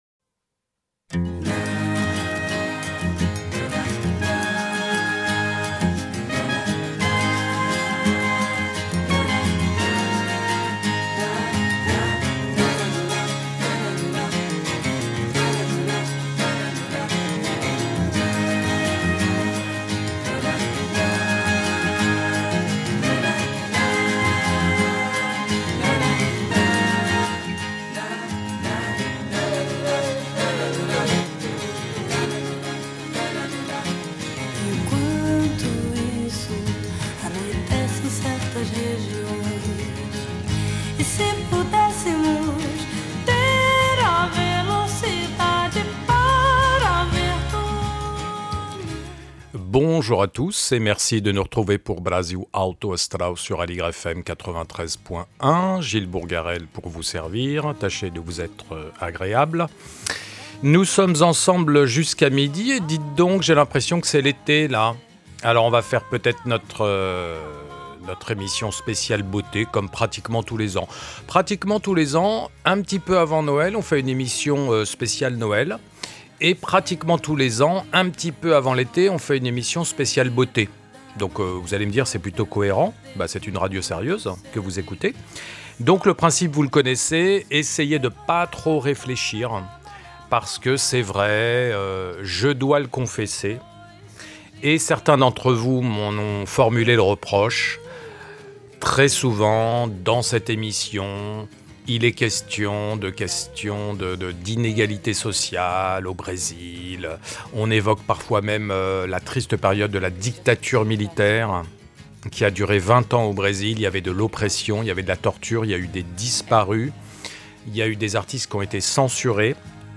Une pause musicale légère pour oublier un peu le fracas du monde.